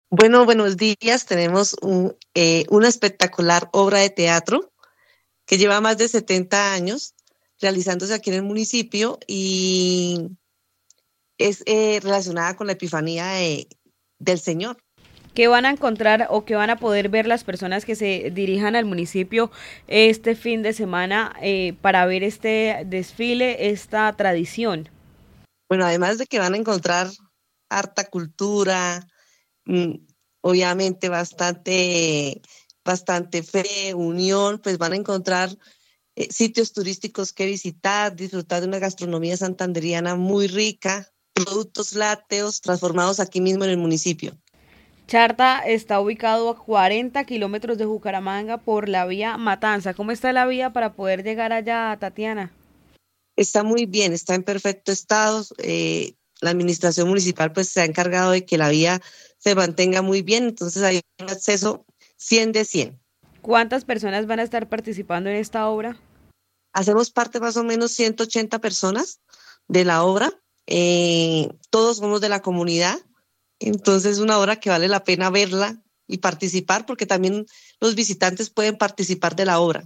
comité organizador